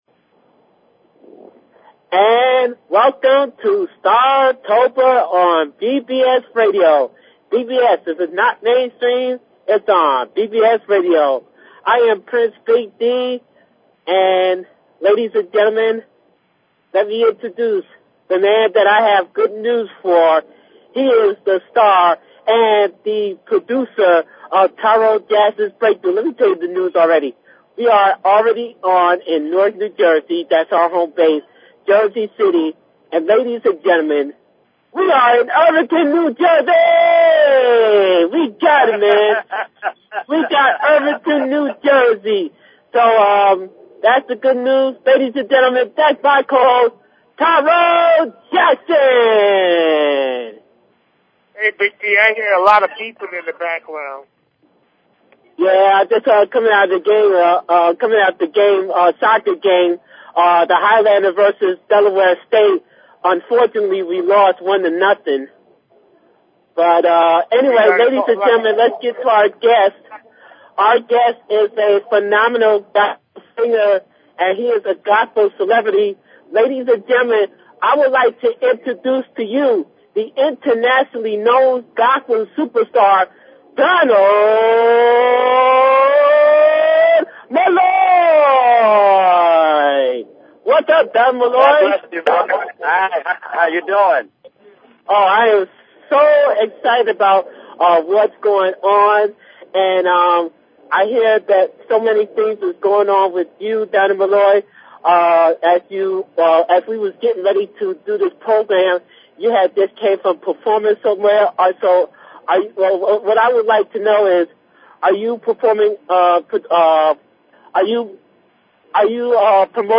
Talk Show Episode
WE TALKED ABOUT HIS UPCOMMING ALBUM AND HIS PAST HITS RECORDED LIVE FROM OUTSIDE AT NJIT!!!